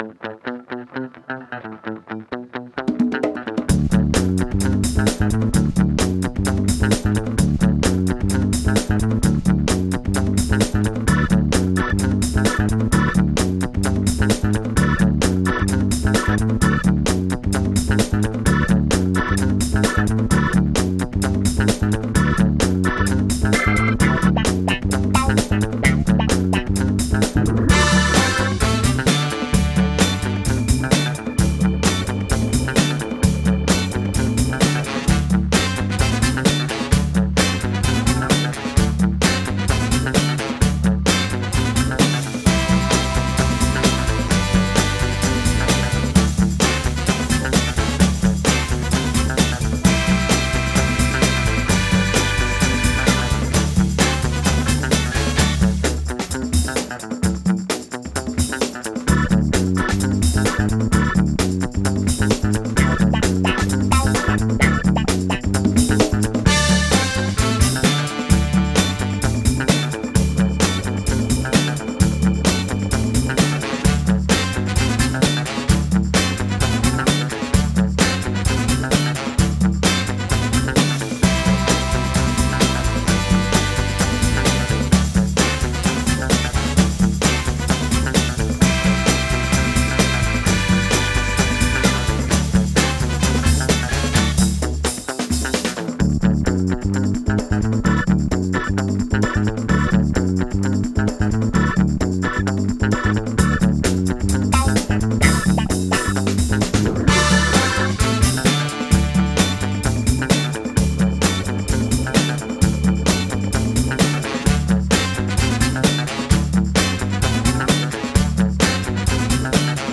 rock
sintetizador
soul